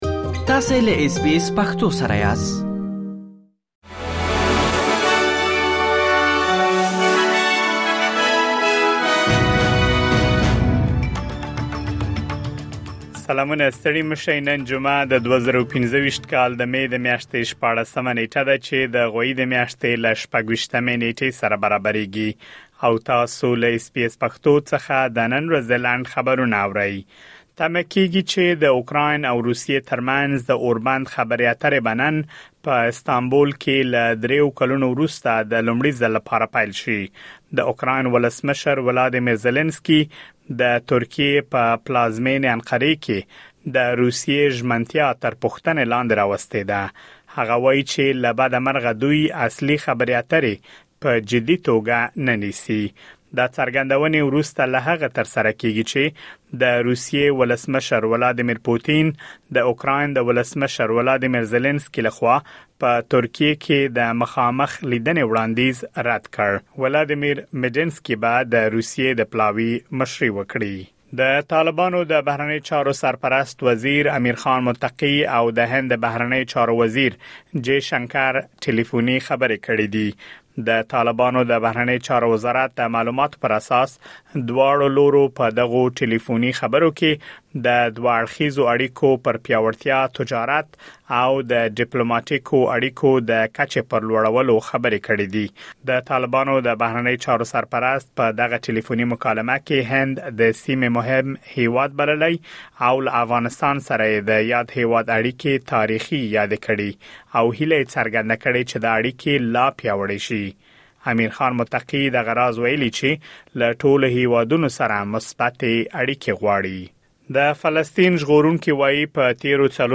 د اس بي اس پښتو د نن ورځې لنډ خبرونه | ۱۶ مې ۲۰۲۵
د اس بي اس پښتو د نن ورځې لنډ خبرونه دلته واورئ.